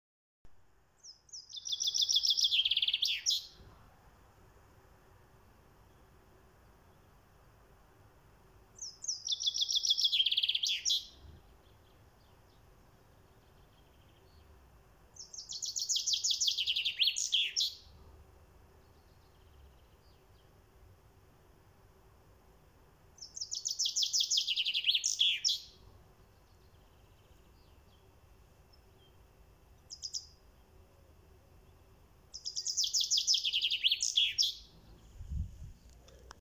Bird Chrip Bouton sonore